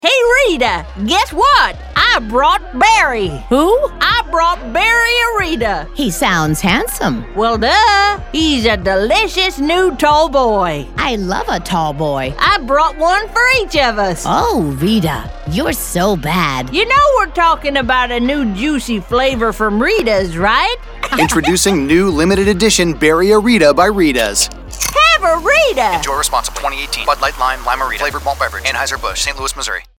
Female – Fun, Characters